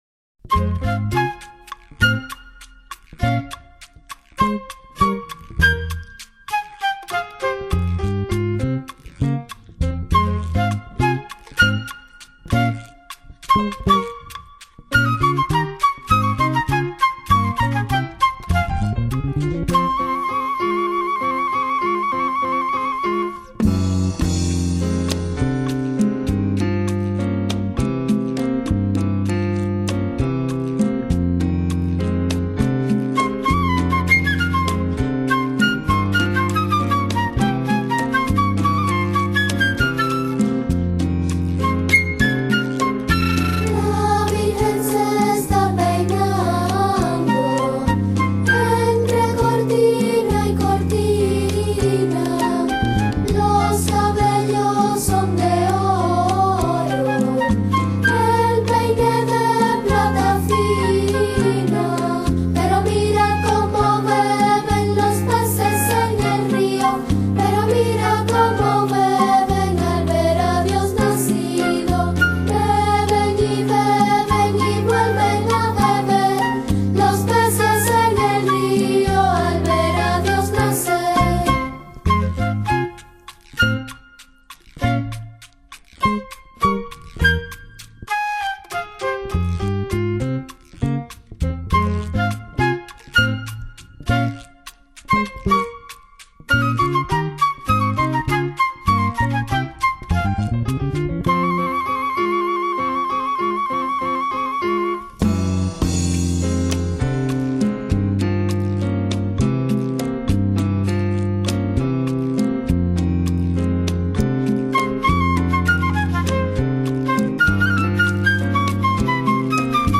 El tradicional villancico español, "Los peces en el río" cantado por un coro de niños y niñas cubanos con ritmo de SON.